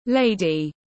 Quý cô tiếng anh gọi là lady, phiên âm tiếng anh đọc là /ˈleɪ.di/.
Lady /ˈleɪ.di/